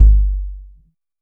RESO KIK#2.wav